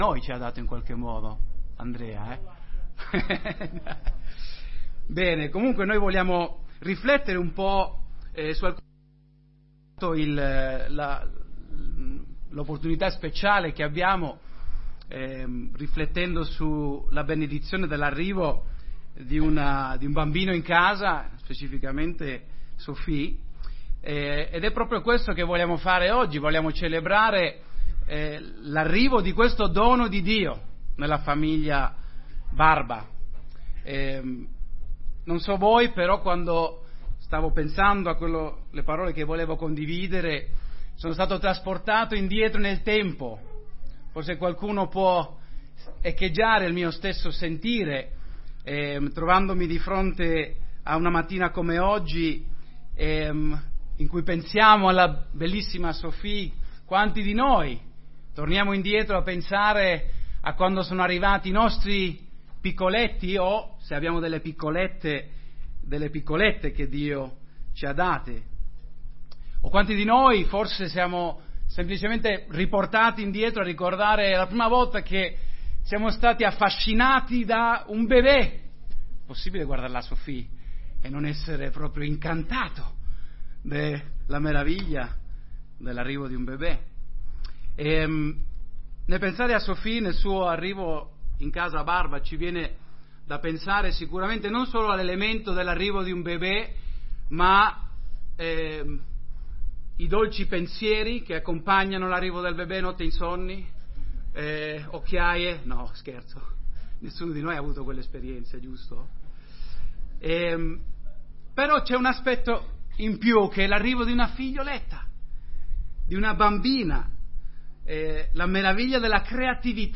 Tutti i sermoni La saggezza 7 Ottobre